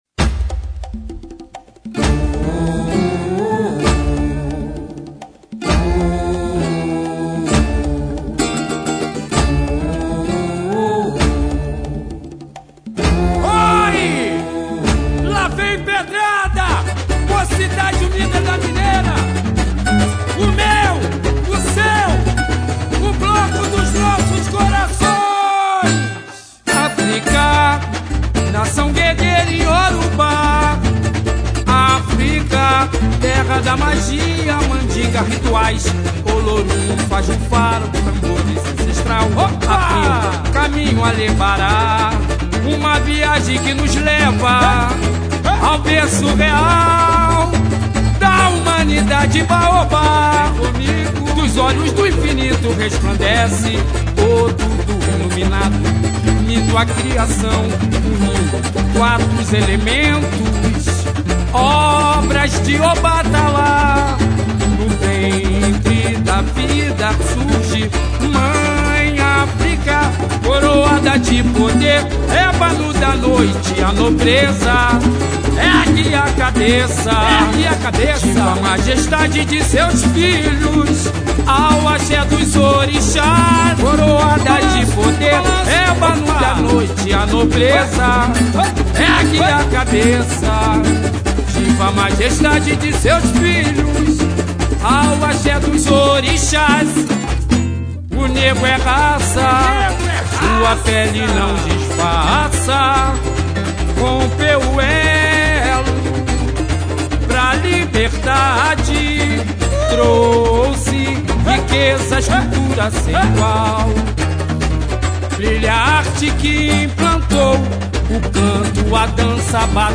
SAMBA-ENREDO: